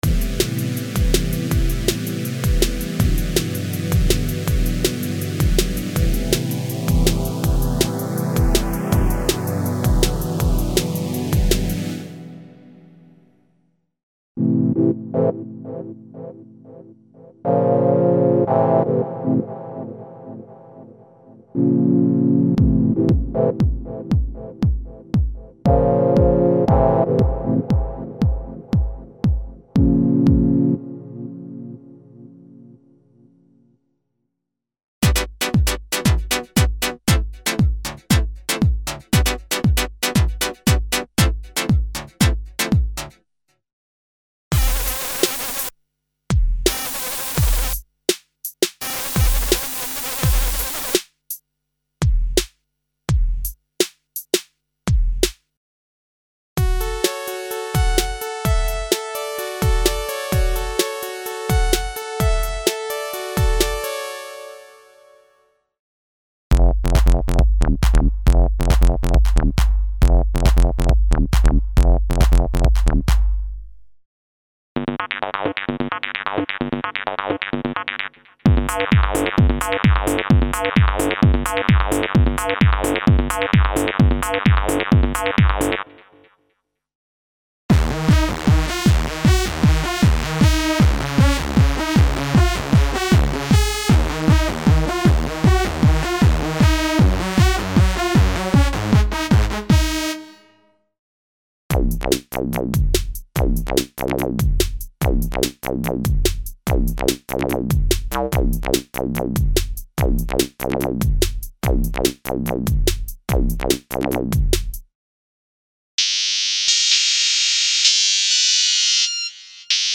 Complete Modern Synth collection of sound programs never before released for Kurzweil K2xxx synthesizers.